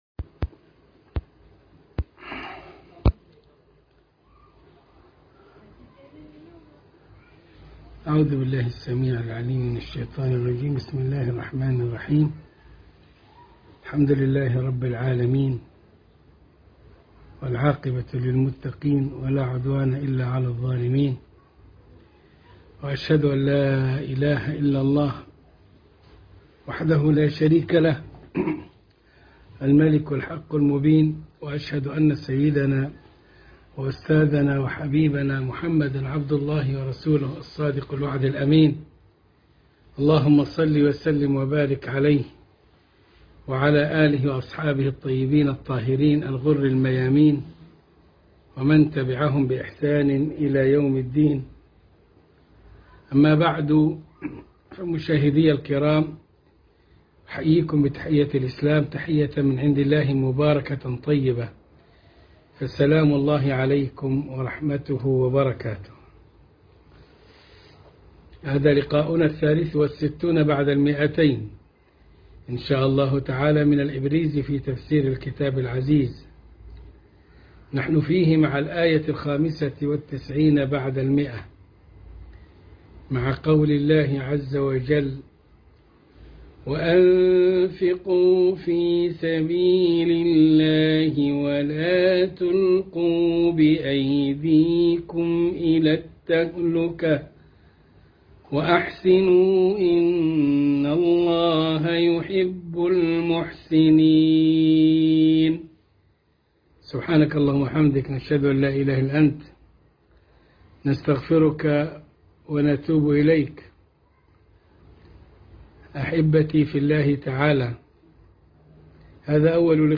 الدرس ٢٦٣ من الإبريز في تفسير الكتاب العزيز سورة البقرة الآية١٩٥ وما بعدها